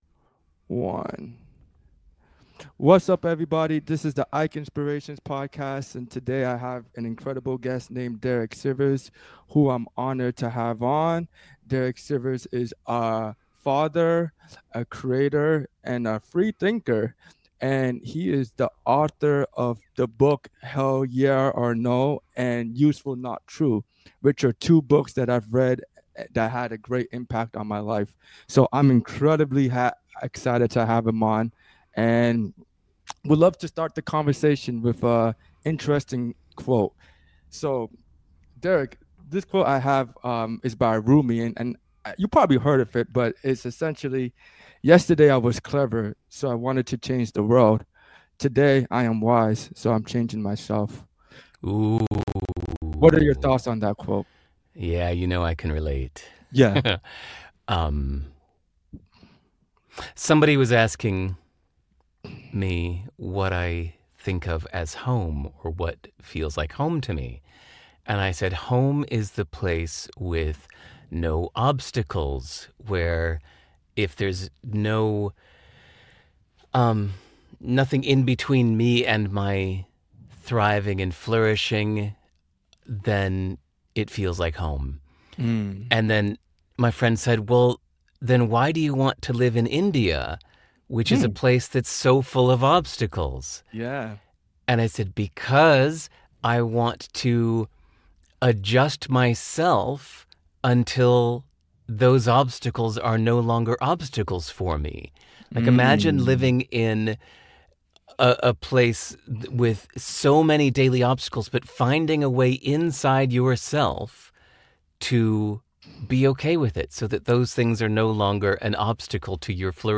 Interviews:
Fun conversation about Useful Not True as applied to religion!